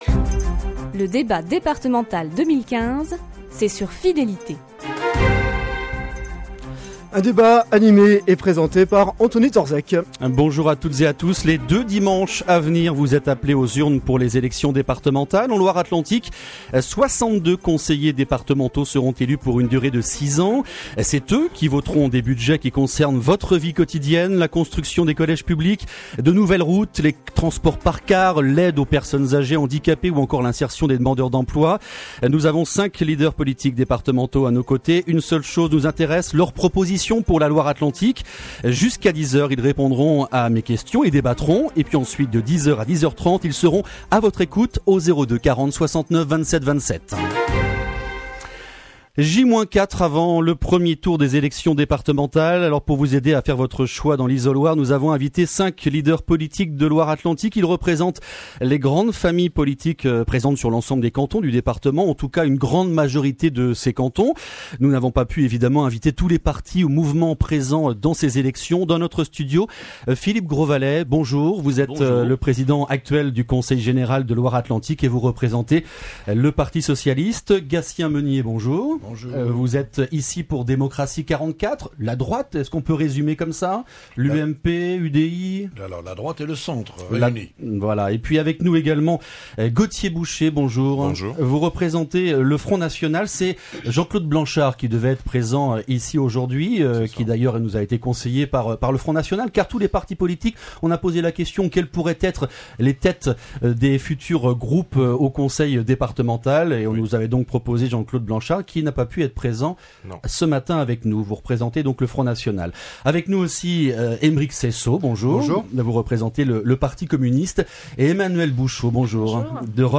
Débat sur Radio Fidélité pour les élections départementales en Loire-Atlantique